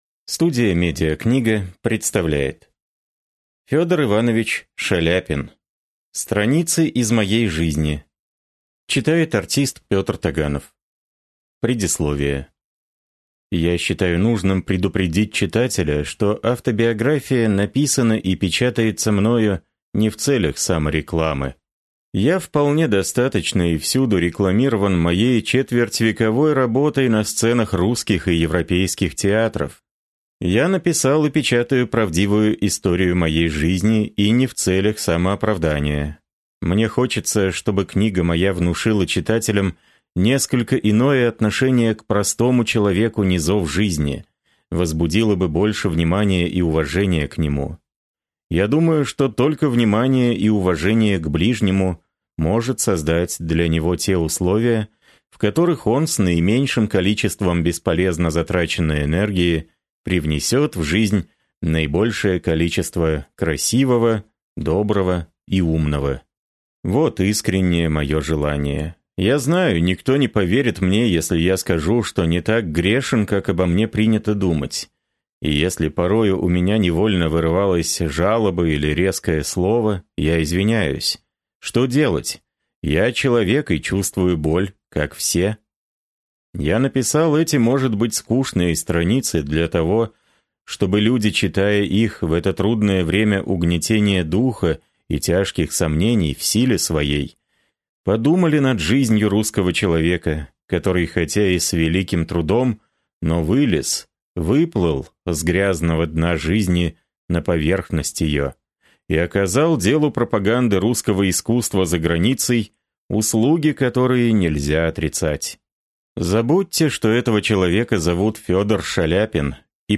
Аудиокнига Страницы из моей жизни | Библиотека аудиокниг